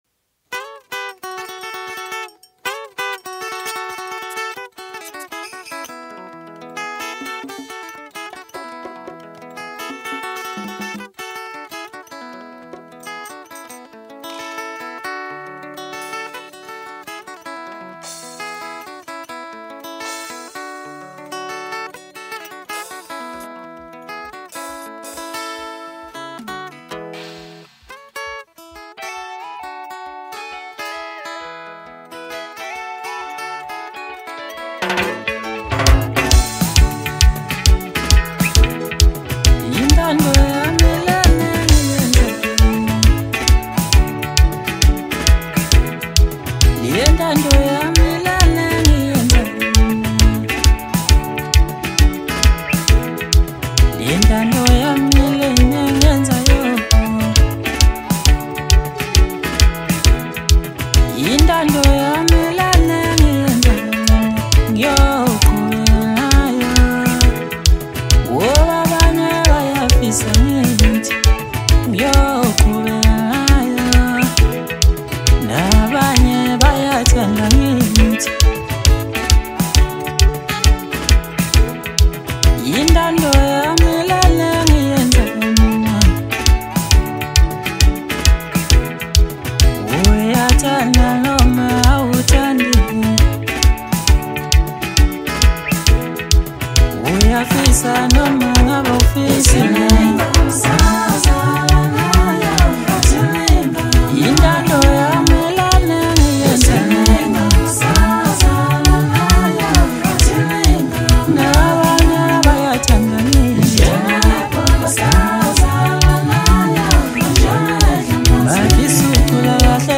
Home » Maskandi » DJ Mix » Hip Hop